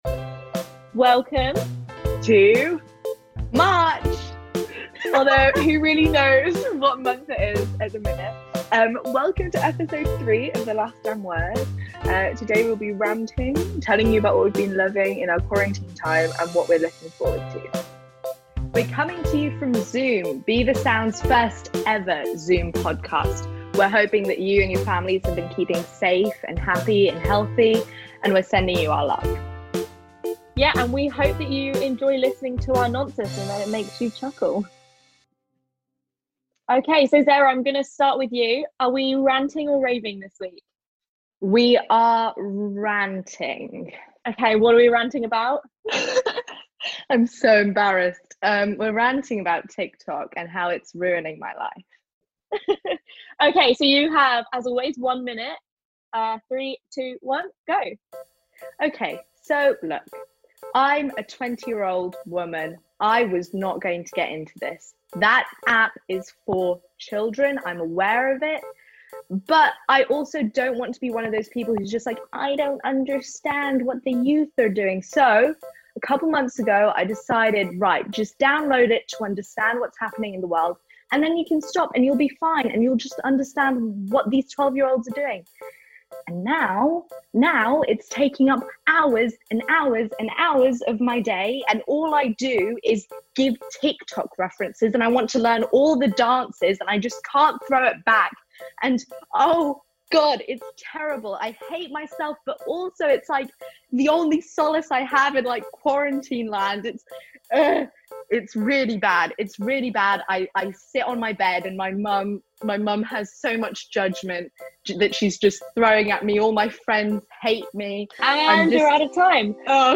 We'll be coming to you at the end of each month to chat about the good, the bad and ugly of arts and culture.